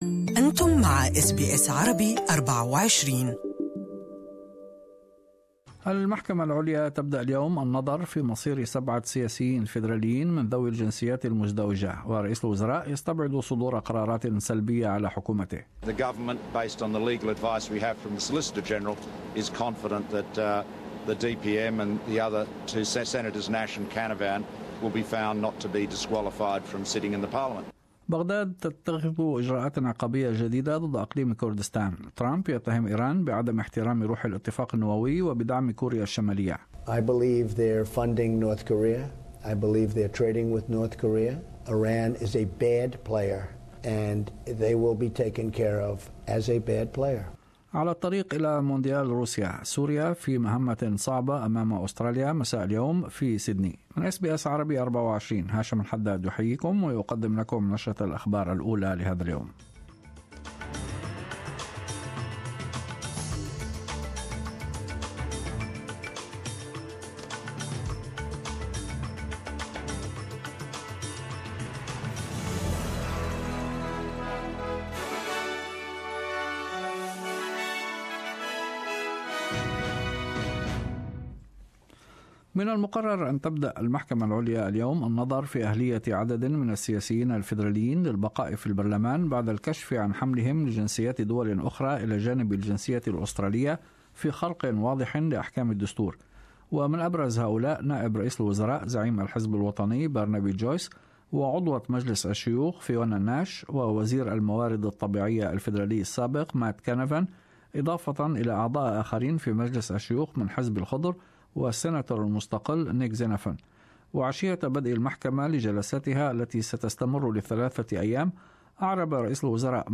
In this bulletin ... ** Turkey's visa dispute with the United States continues ** Britain and the European Union clash over who should make progress in Brexit talks and ** The Socceroos gearing up for tonight's clash with Syria